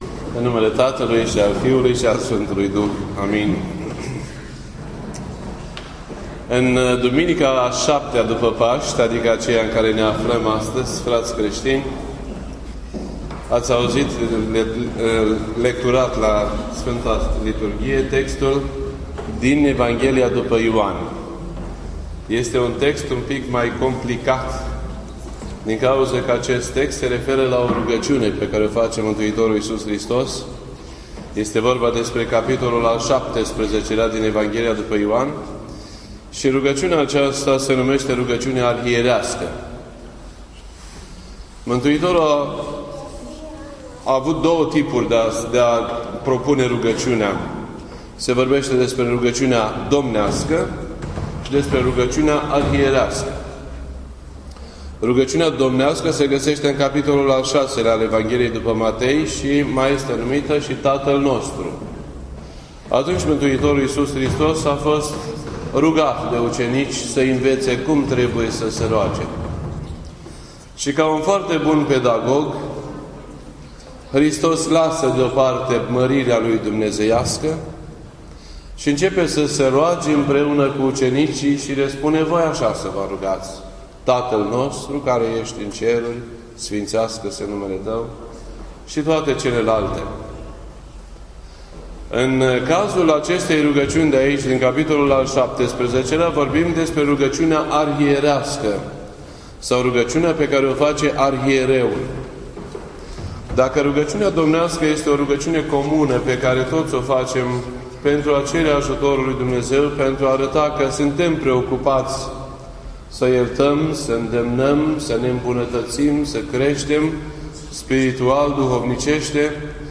This entry was posted on Sunday, May 24th, 2015 at 4:08 PM and is filed under Predici ortodoxe in format audio.